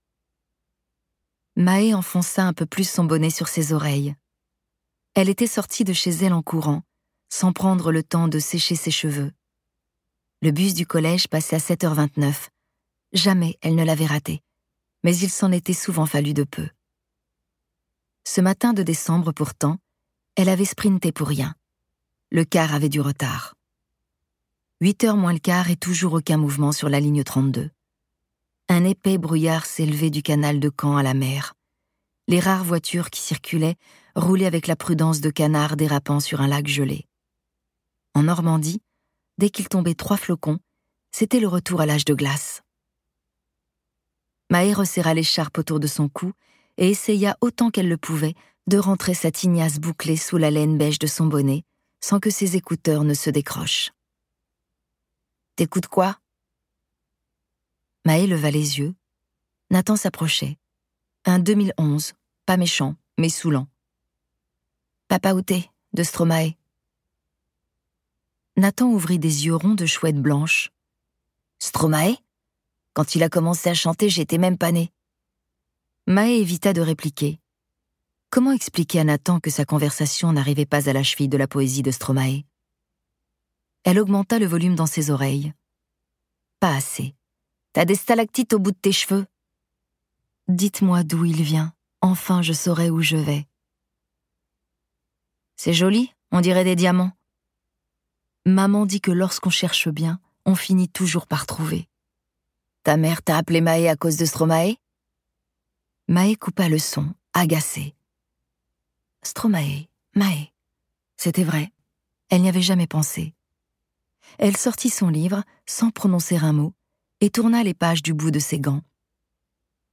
Une quête des origines incarnée par un trio de comédiens !